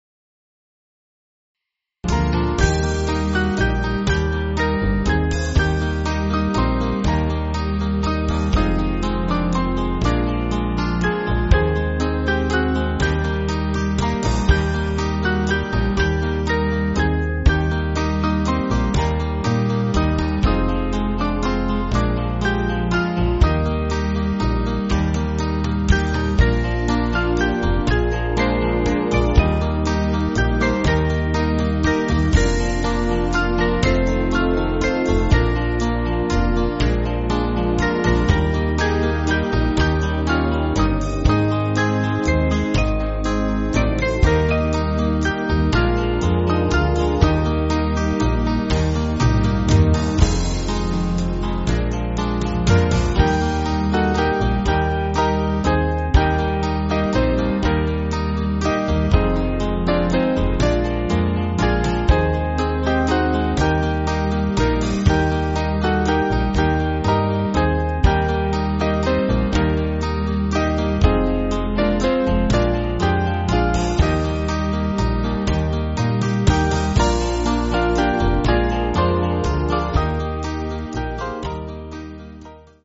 Small Band
(CM)   3/Eb